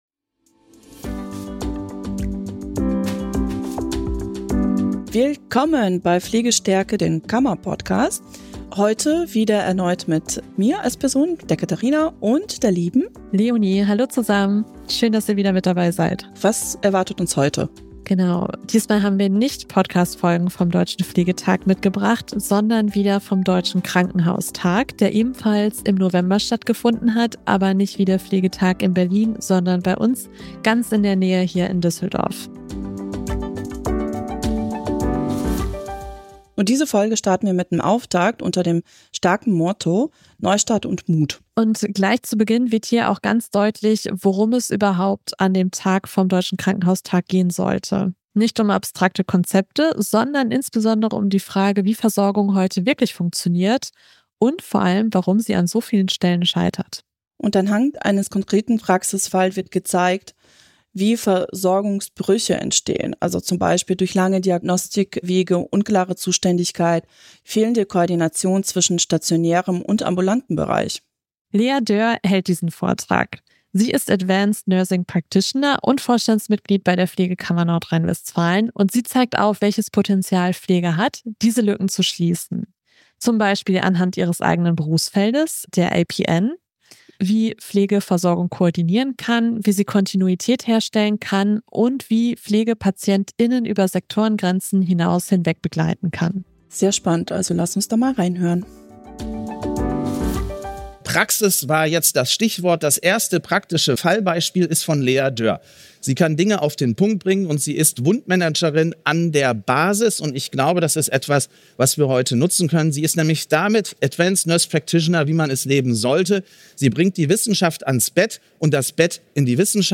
In dieser Folge vom Deutschen Krankenhaustag in Düsseldorf steht deshalb ein konkreter Praxisfall im Mittelpunkt,...
Der Vortrag macht klar, dass bessere Versorgung nicht nur neue Konzepte braucht, sondern professionelle Pflege mit erweiterten Handlungsspielräumen, die Versorgung organisiert, vernetzt und patientenzentriert gestaltet.